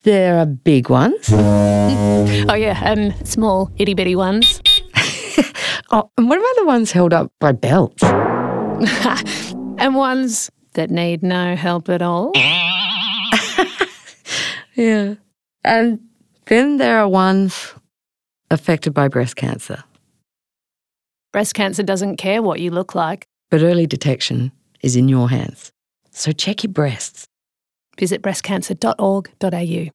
Female comedians urge women to check their breasts for cancer in radio ads
The radio campaign features comedians Fiona O’Laughlin and Claire Hooper talking about the different names, types and the best way to check for cancer.